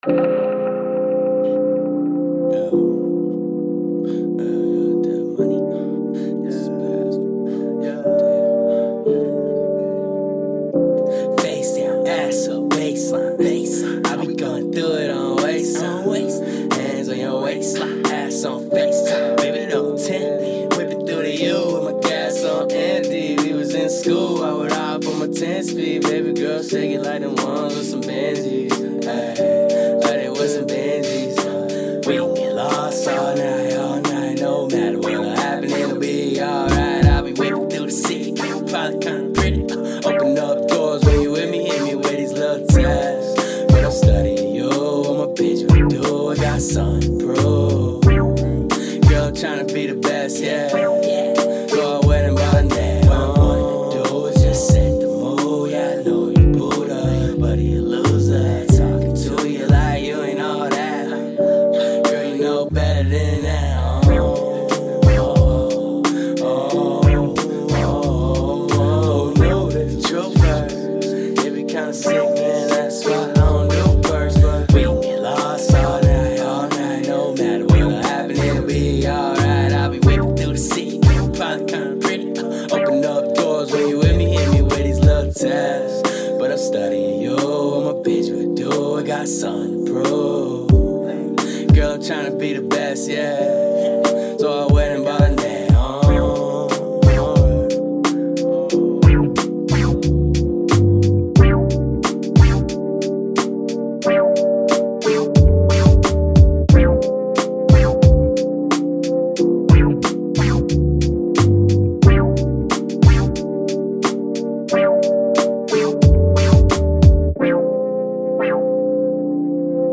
A smooth bouncy song with a fun laidback feel.